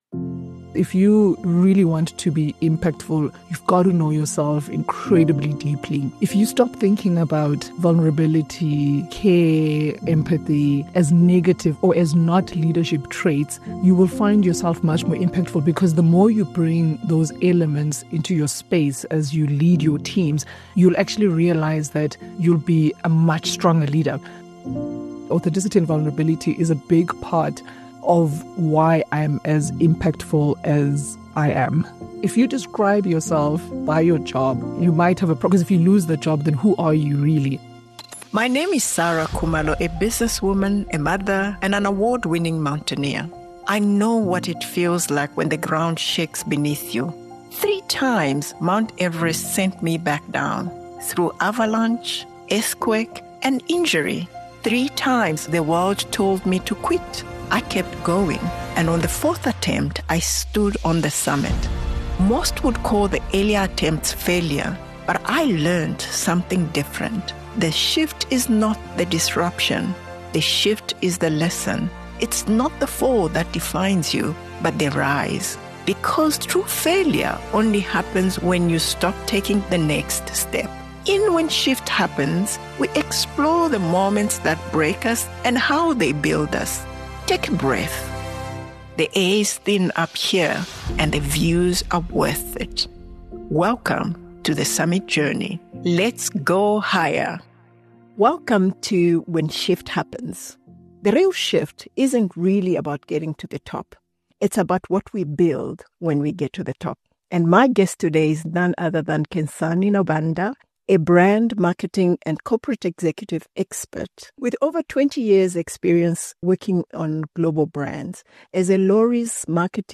This conversation offers powerful lessons for leaders navigating uncertainty, pressure and change.